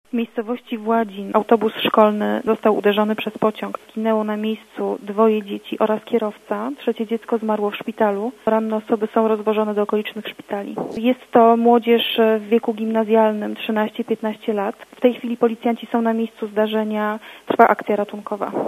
kolej_-_wypadek.mp3